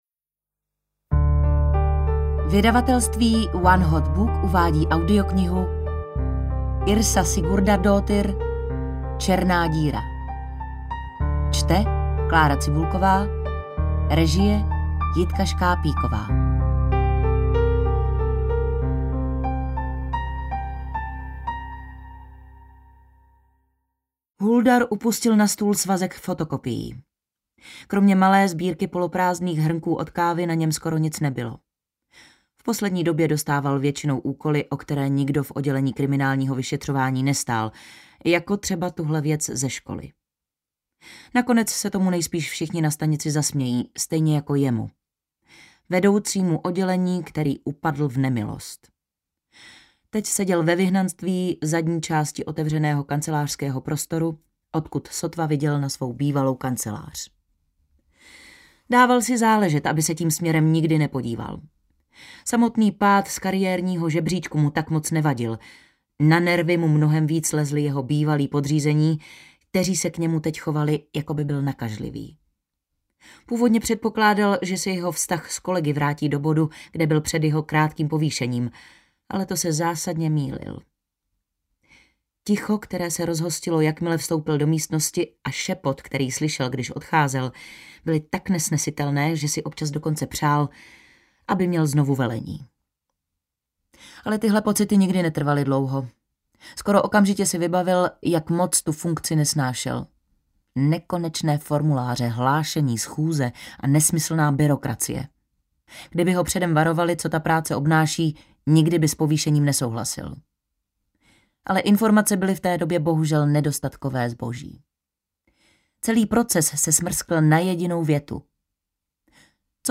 Černá díra audiokniha
Ukázka z knihy
• InterpretKlára Cibulková